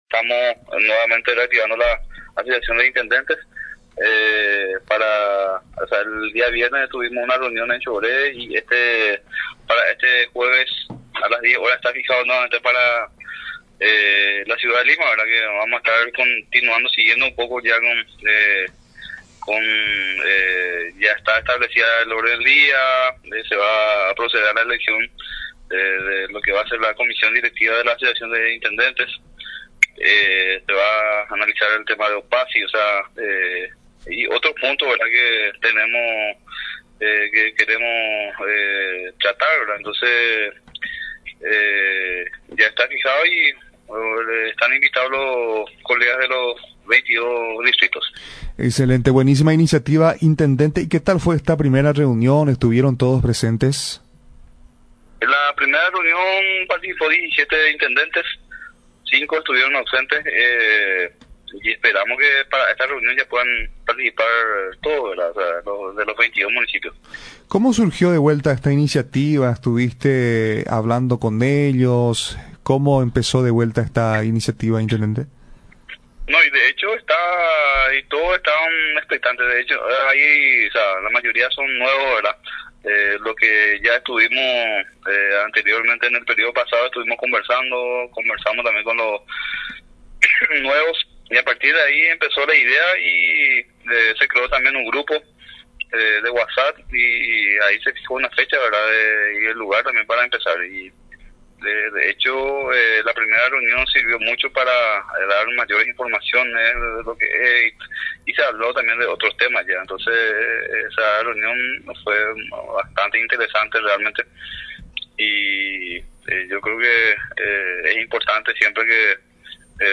El Abg. Juan Manuel Avalos, jefe comunal del distrito de Lima, en comunicación con Radio Nacional, informó de la reunión que se llevó a cabo durante la semana pasada en la ciudad de Choré, donde participaron 17 intendentes de diferentes distritos del segundo departamento.